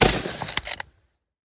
lee_enfield_firing_sounds.ogg